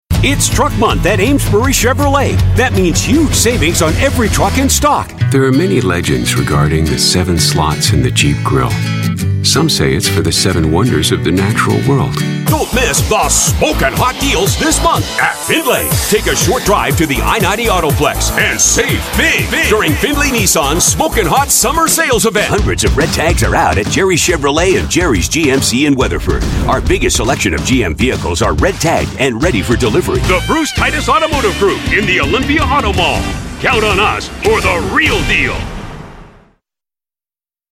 English (Canadian)
Automotive
Sennheiser MKH 416 microphone
Custom built voice booth
BaritoneBassDeep
ConfidentGroundedSeriousAuthoritativeConversationalCorporateExperienced